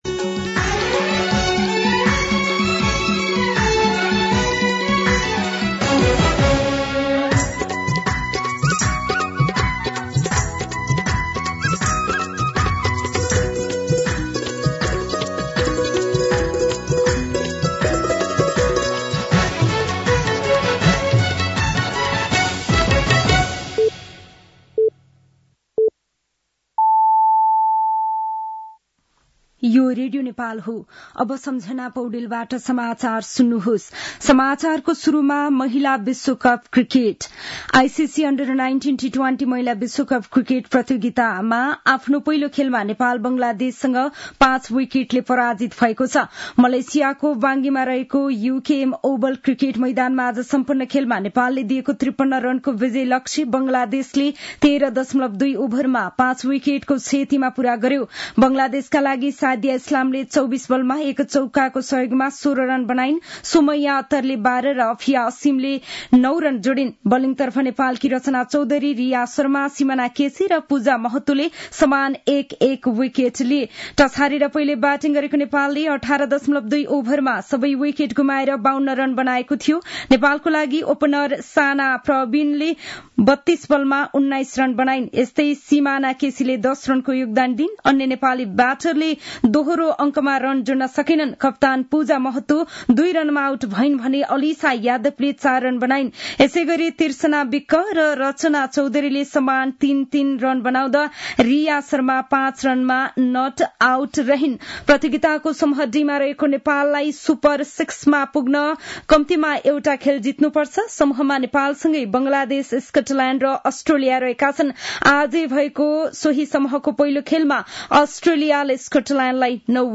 दिउँसो ४ बजेको नेपाली समाचार : ६ माघ , २०८१
4pm-Nepali-News.mp3